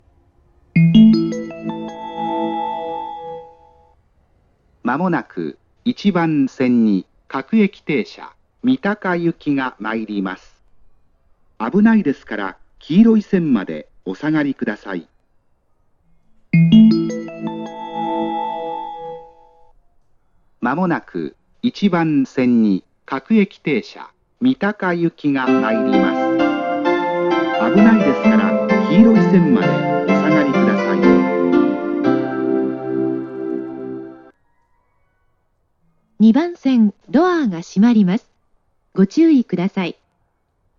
発車メロディー
接近に発車メロディが被ってます。
●音質：良